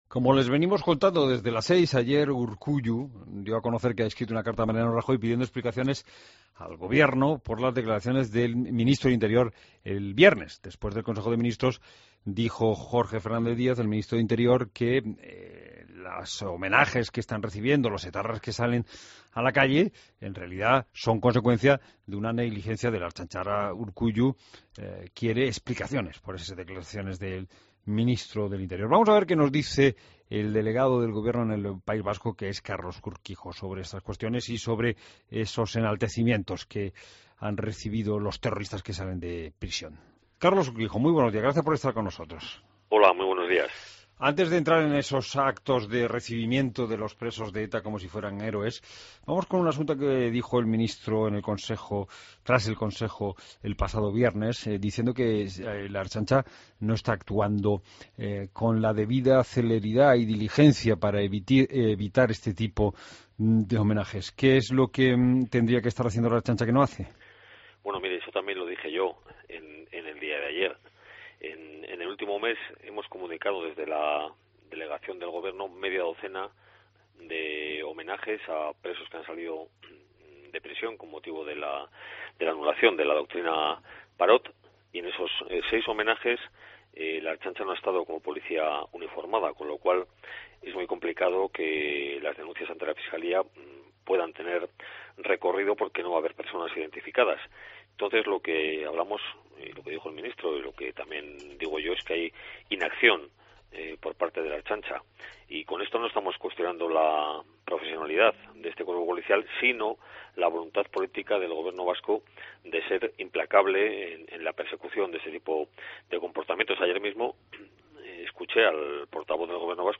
Entrevista a Carlos Urquijo, Delegado del Gobierno Vasco